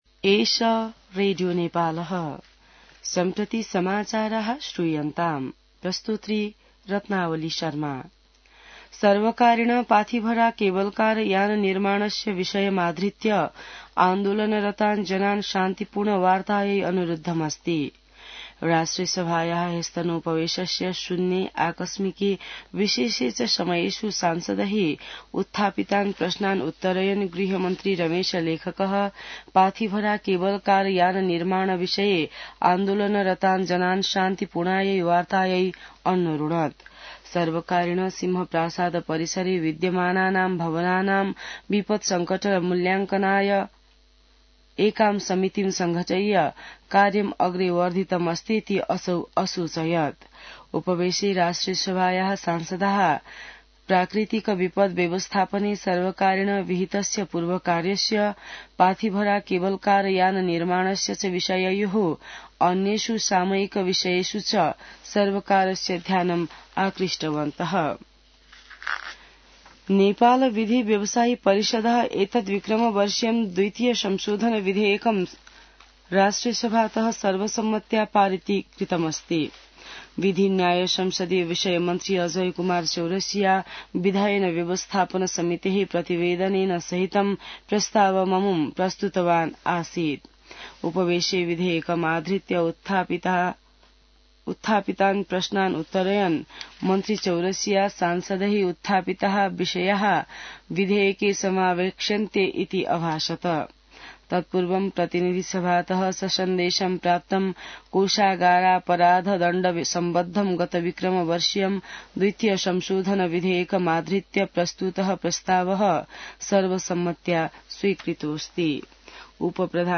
संस्कृत समाचार : २० फागुन , २०८१